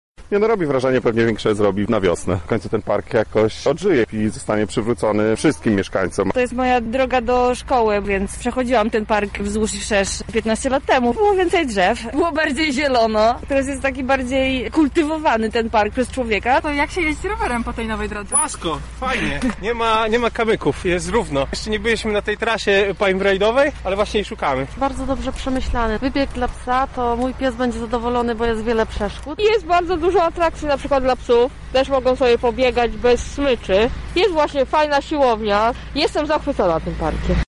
Również my wybraliśmy się na spacer i zapytaliśmy o wrażenia napotkanych lublinian: